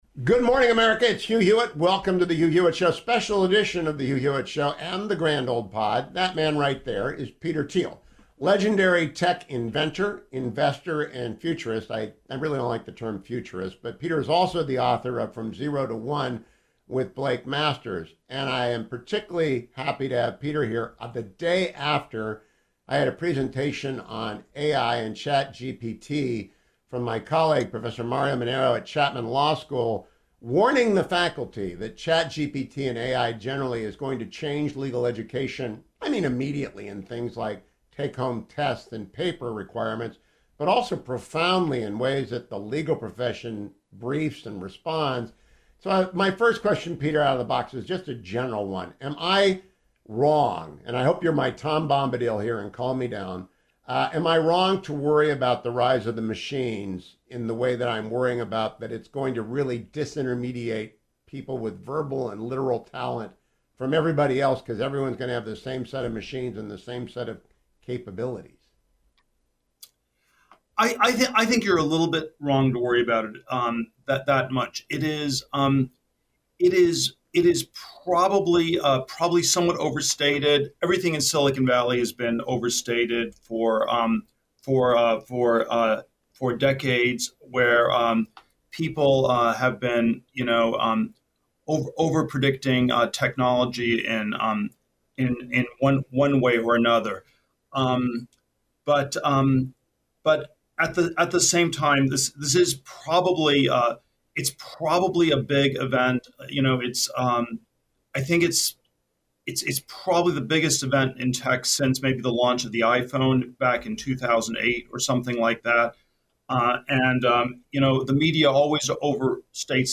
Peter Thiel joined me for the Grand Old Pod this AM. Here is the audio and transcript of that long conversation.